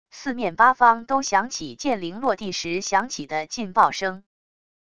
四面八方都响起剑灵落地时响起的劲爆声wav音频